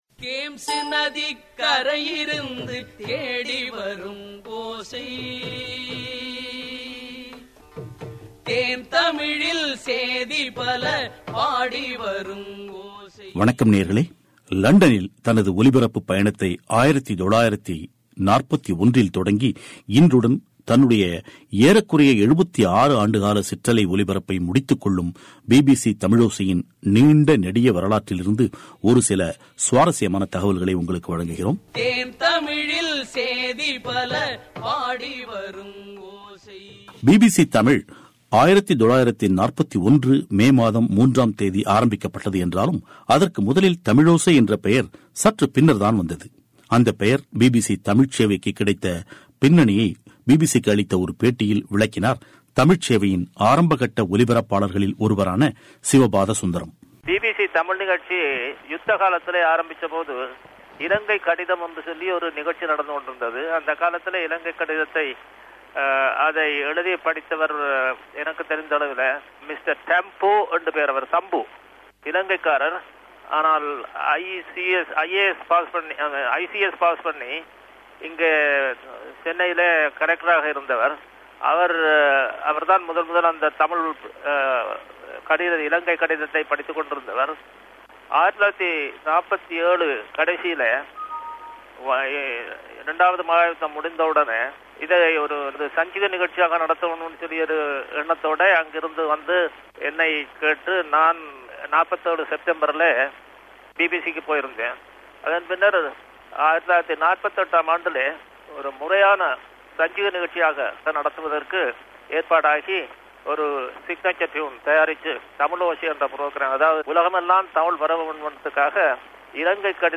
கடந்த 76 ஆண்டுகளாக தமிழ் கூறும் நல்லுலகெங்கும் நேயர்களுக்கு செய்திகளை வழங்கி வந்த பிபிசி தமிழோசையின் சிற்றலை ஒலிபரப்பு நிறுத்தப்படும் நிலையில், இந்த நீண்ட கால கட்டத்தில் பிபிசி தமிழில் ஒலிபரப்பான முக்கிய நிகழ்ச்சிகளிலிருந்து சில ஒலிக்கீற்றுகள் அடங்கிய சிறப்புப் பெட்டகம் இது.